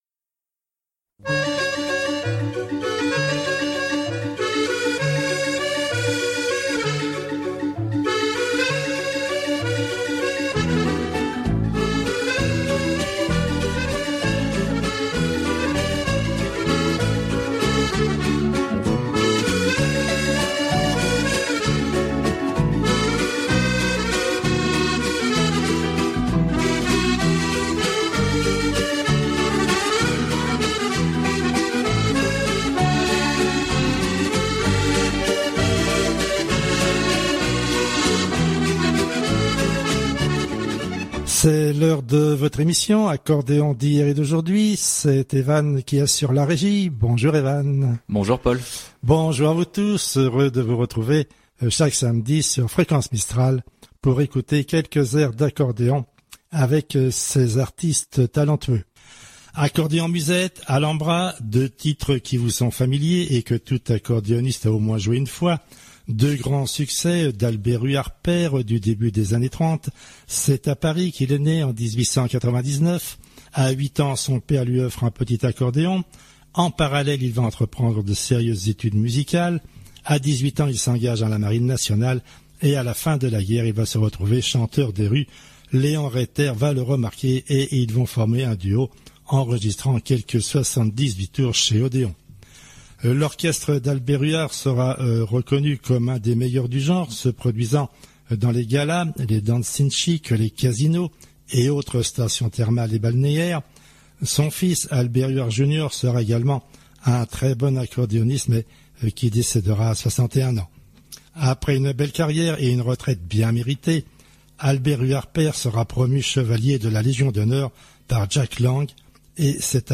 Le programme de l'émission du jour